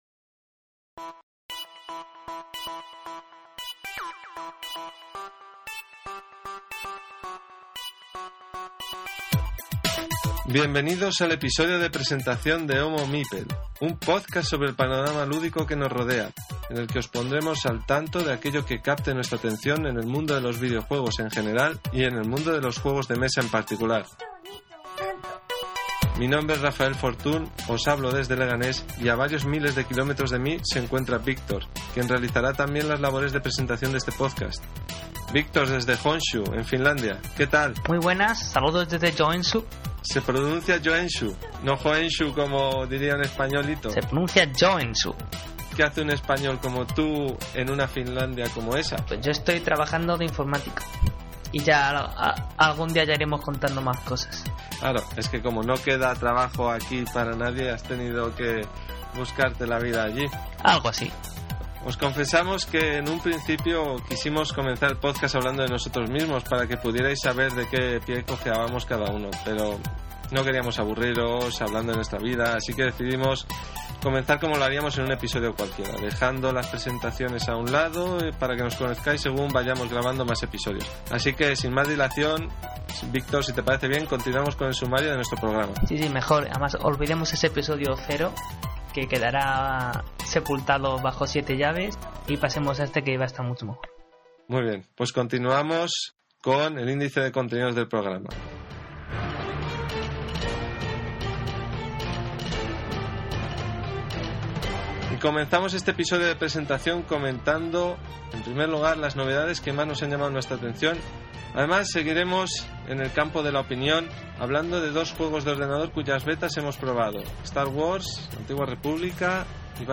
Son 53 minutos de análisis, comentarios, risas y alguna que otra metedura de pata (que escucharéis al final del episodio).